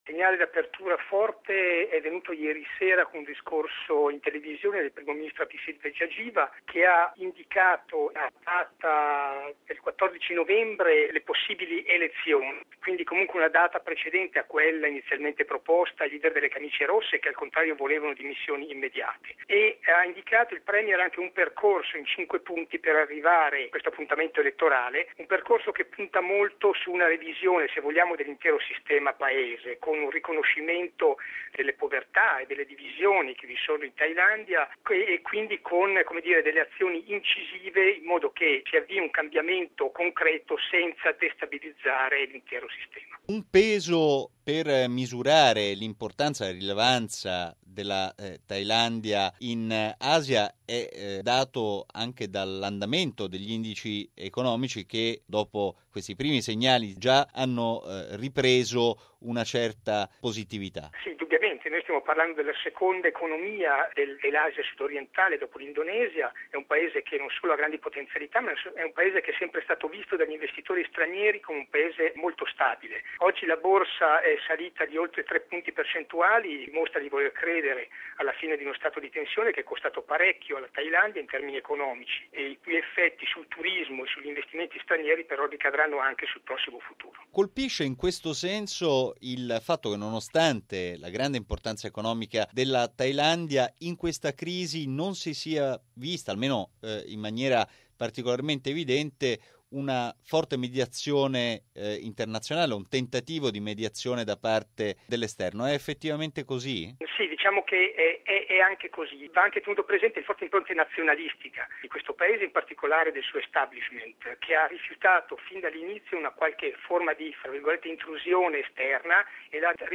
raggiunto telefonicamente a Bangkok